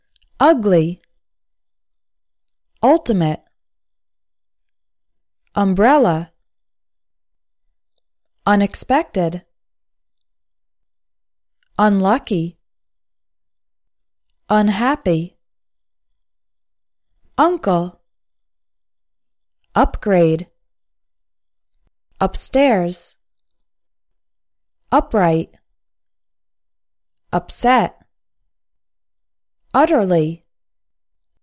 English words starting with U – “uh” sound